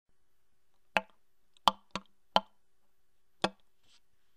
Bull Elk Sounds The Bull Elk make the most magnificent and powerful sounds. This is done to chase off, challenge, and establish dominance over the other Bull Elk. The Glunk
the_glunk.wma